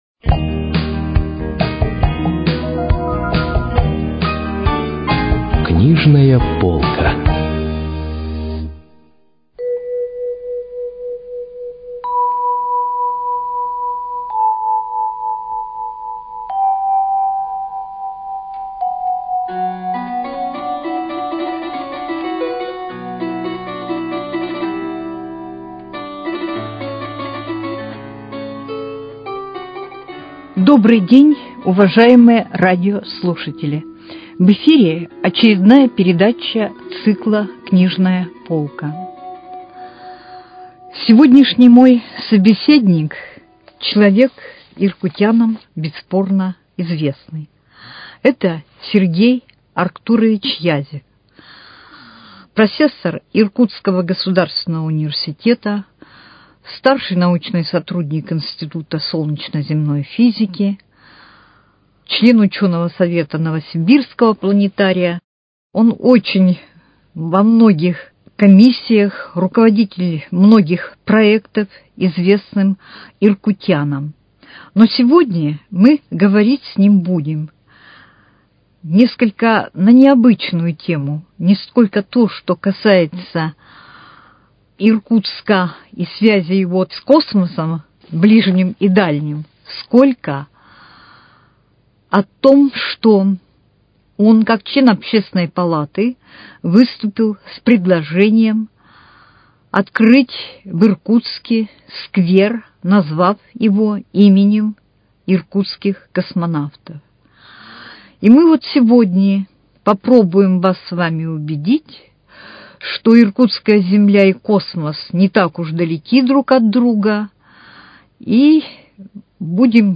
Книжная полка: Беседа